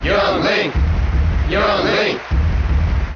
File:Young Link Cheer JP Melee.ogg
Young_Link_Cheer_JP_Melee.ogg.mp3